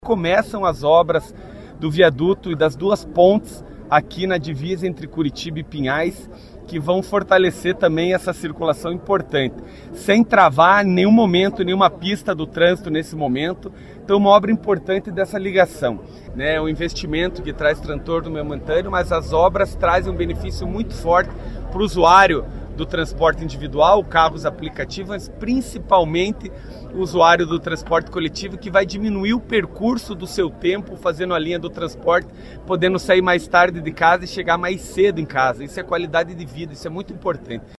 O prefeito Eduardo Pimentel enfatizou a importância da obra, e os benefícios que ela vai trazer para os usuários.
SONORA-VIADUTO-CURITIBA-PINHAIS.mp3